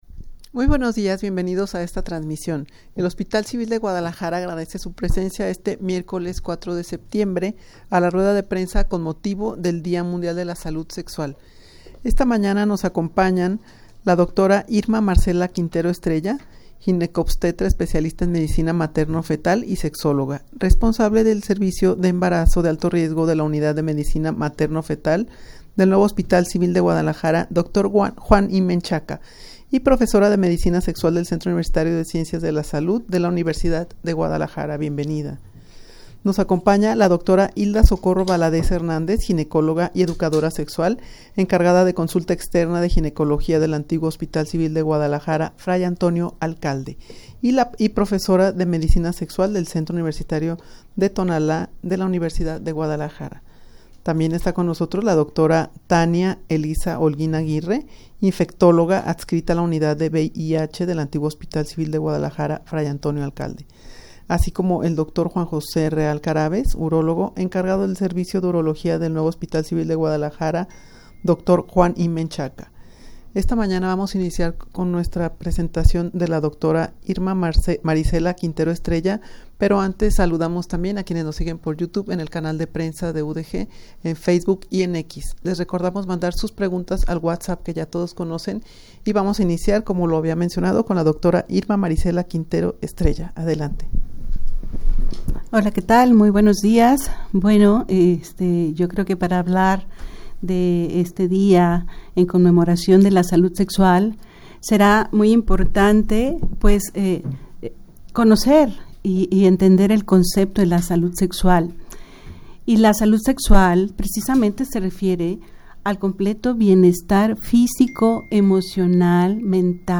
Audio de la Rueda de Prensa
rueda-de-prensa-con-motivo-del-dia-mundial-de-la-salud-sexual.mp3